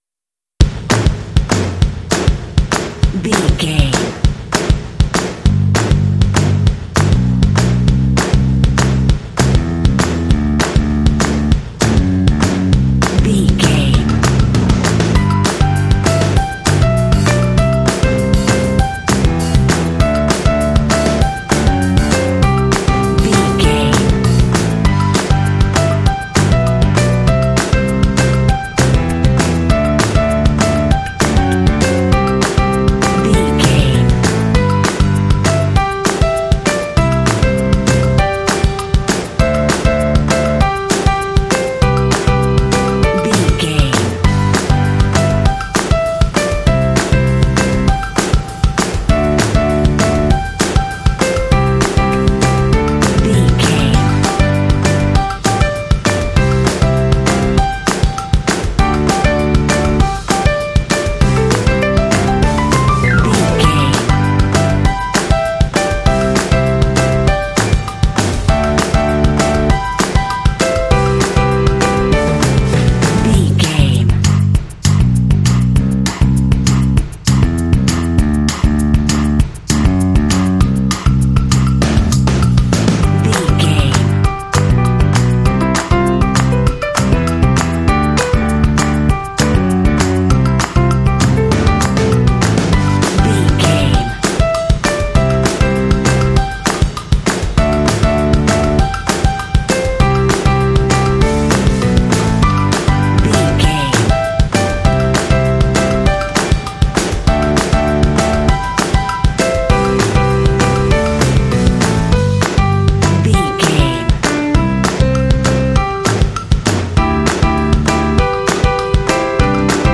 Uplifting
Ionian/Major
cheerful/happy
joyful
energetic
lively
drums
percussion
bass guitar
piano
indie